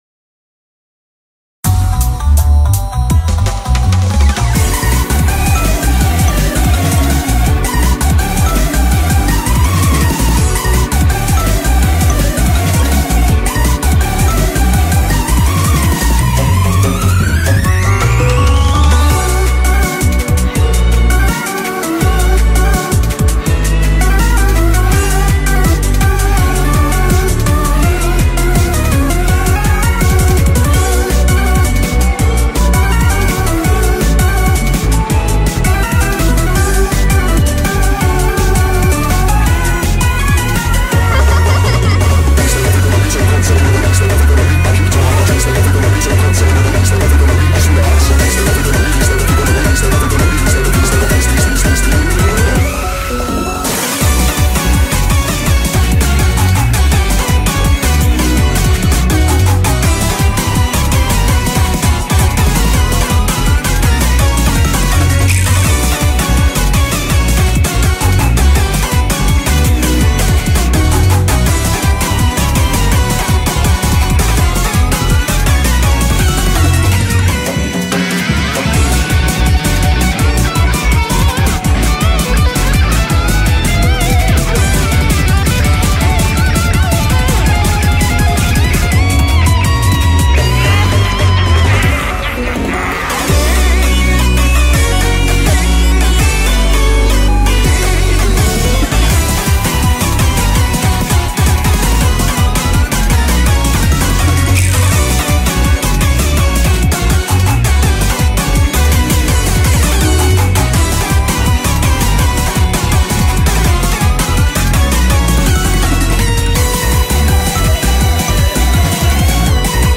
BPM165
Audio QualityCut From Video